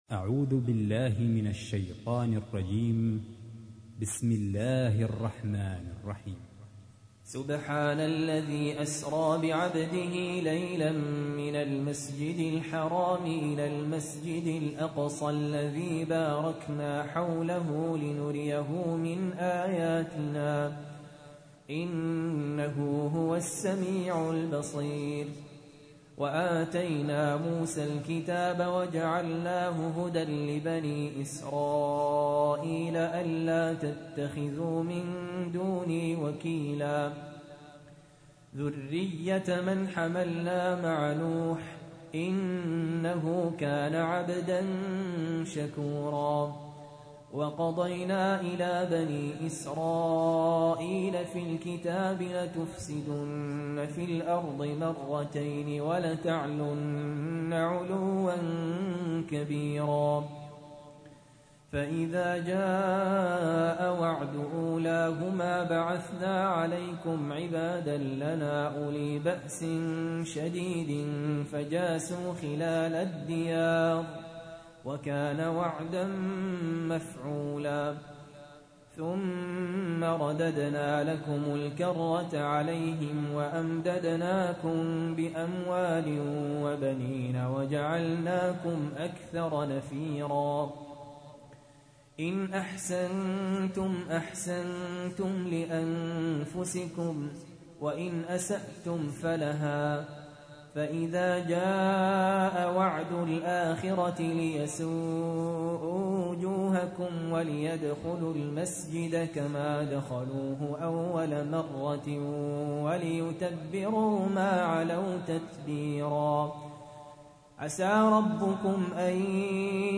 تحميل : 17. سورة الإسراء / القارئ سهل ياسين / القرآن الكريم / موقع يا حسين